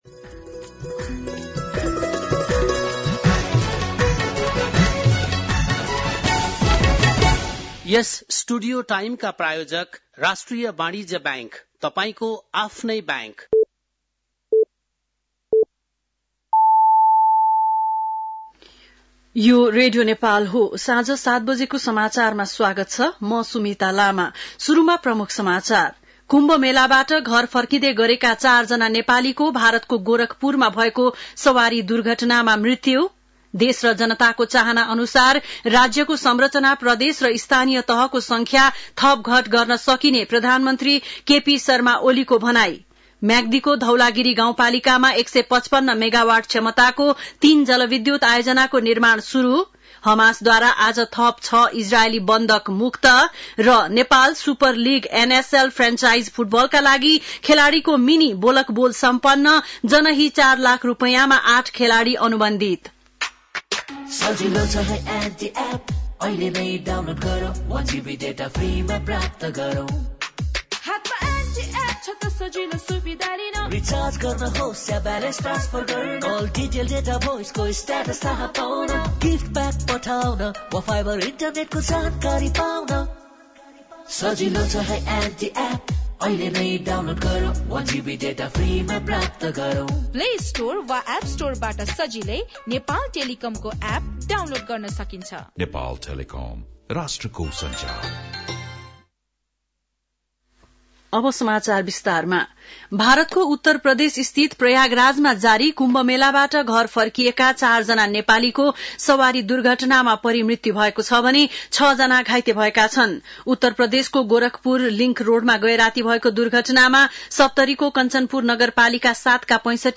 बेलुकी ७ बजेको नेपाली समाचार : ११ फागुन , २०८१